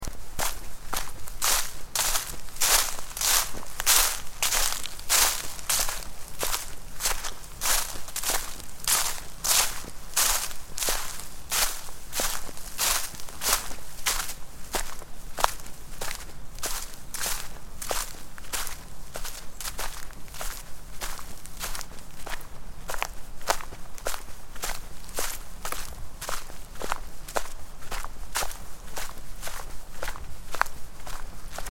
دانلود آهنگ جنگل 7 از افکت صوتی طبیعت و محیط
دانلود صدای جنگل 7 از ساعد نیوز با لینک مستقیم و کیفیت بالا
جلوه های صوتی